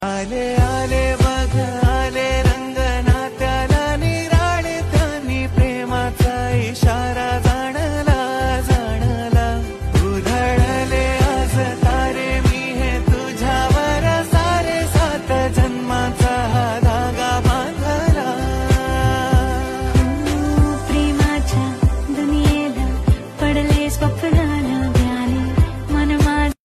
vibrant and energetic essence of Marathi music
rhythmic beats